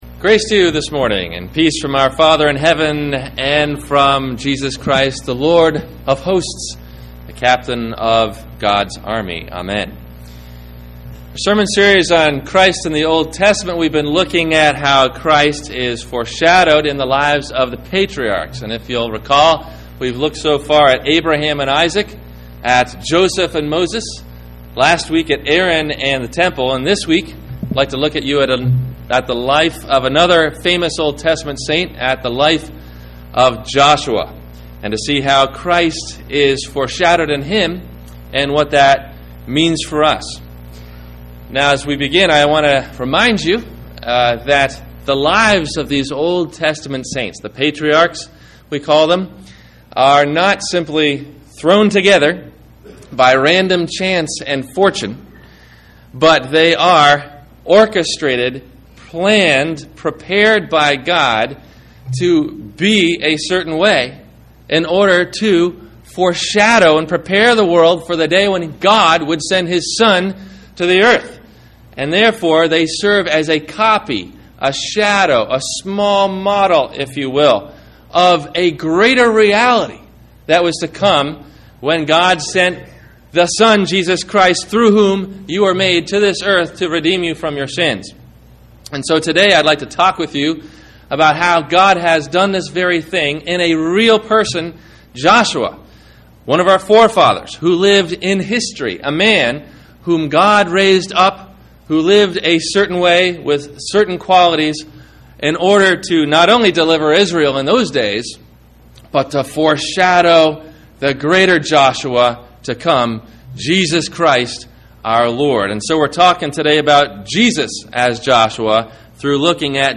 The Greater Joshua – Sermon – March 29 2009